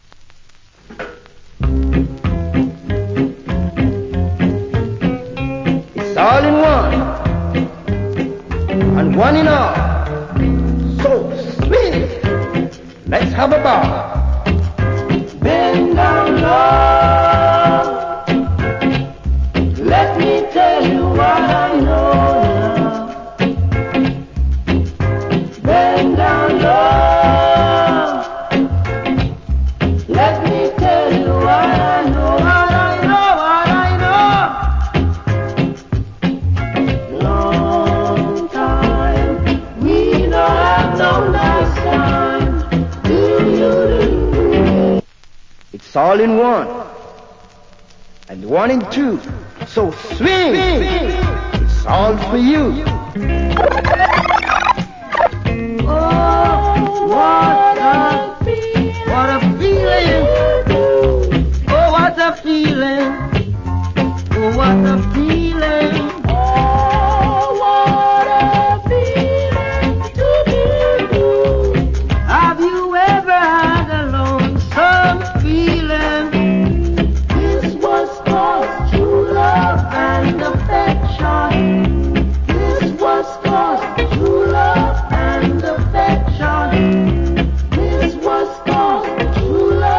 Great Roots Rock Vocal. Medley.